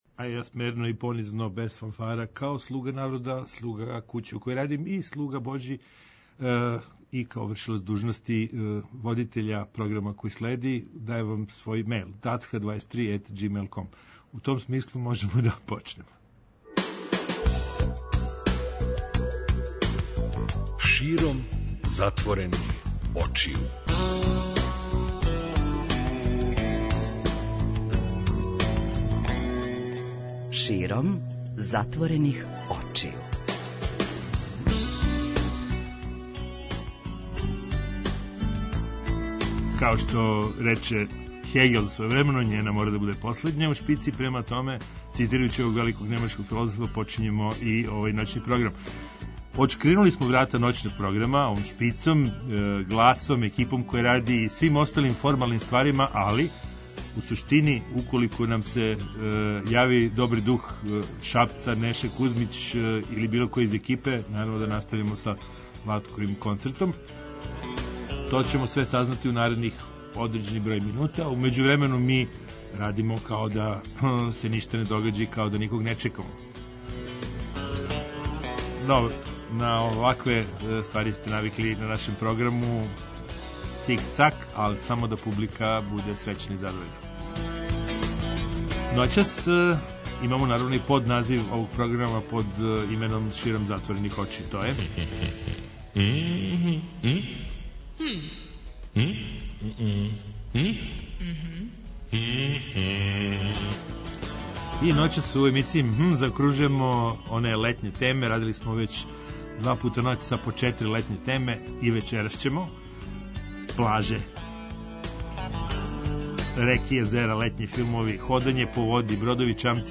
Ноћни програм Београда 202.